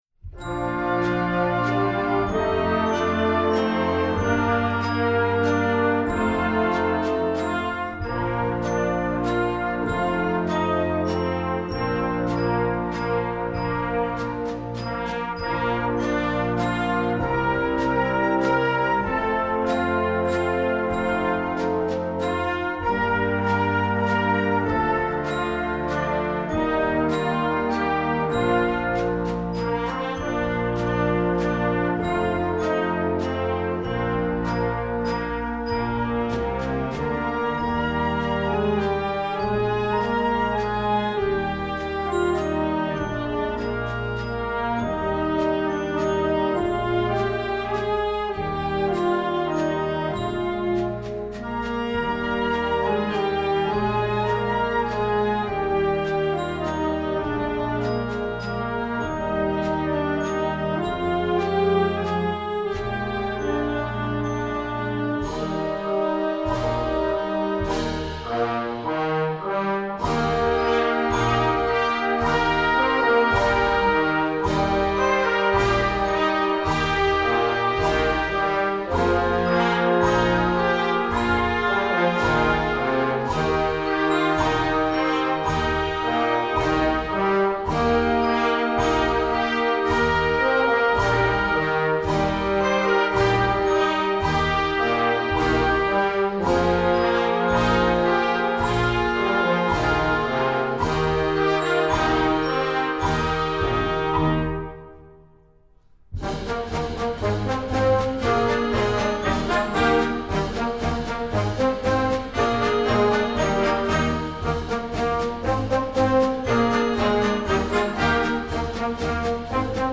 instructional, children